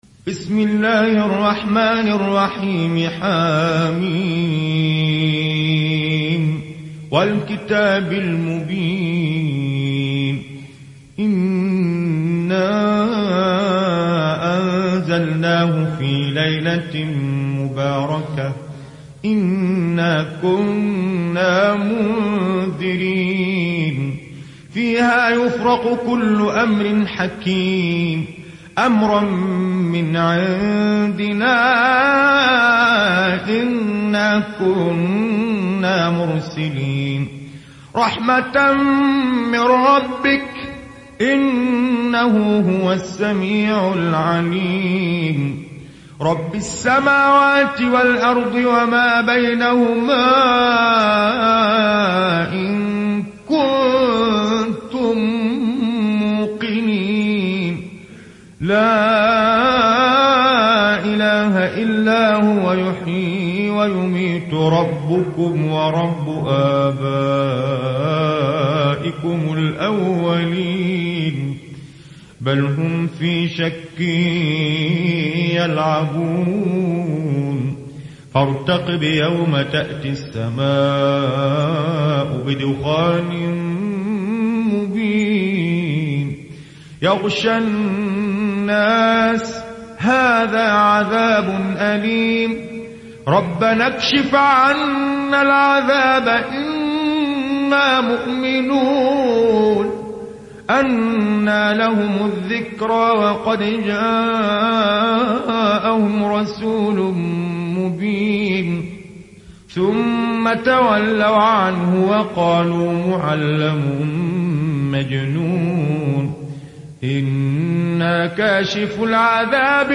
Riwayat Hafs an Assim